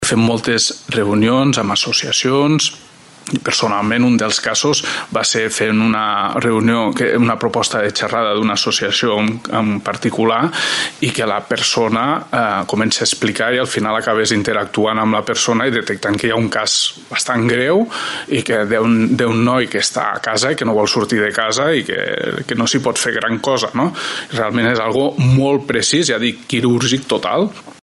Per la seva banda, el secretari d’Estat de Joventut i Esports, Alain Cabanes, ha detallat les novetats del programa,